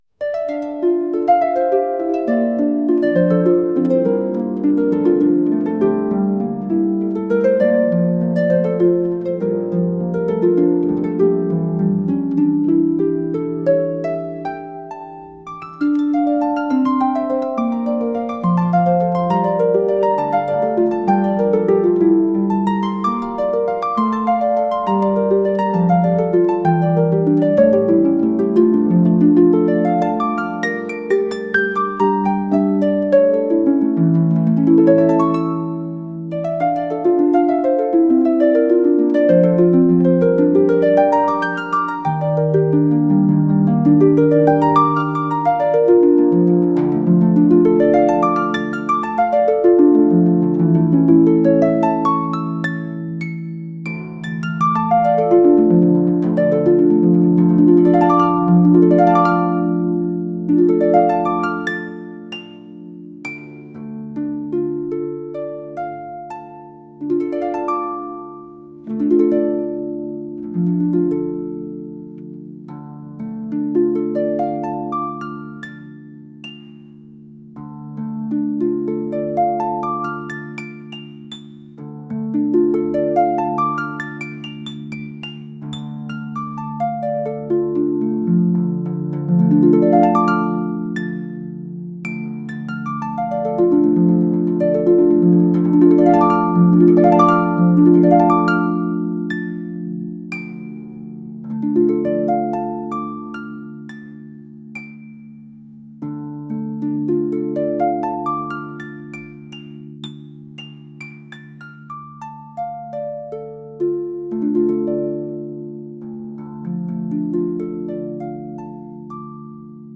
calm Music